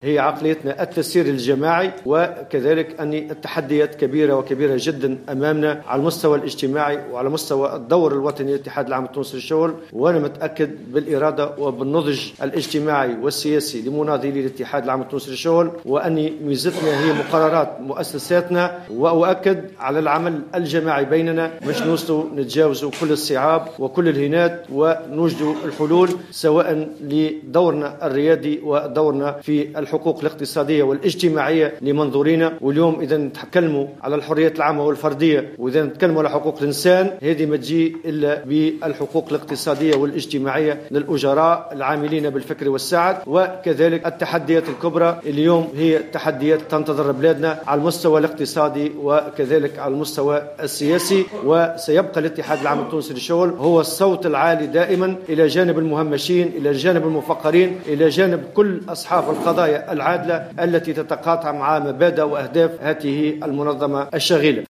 وأكد في كلمة له اليوم خلال اجتماع المكتب التنفيذي الموسع للاتحاد على أهمية العمل الجماعي من أجل تجاوز كل الصعوبات والهنات، مضيفا أن التحديات مازالت كبيرة، خاصة على المستوى الاجتماعي والاقتصادي.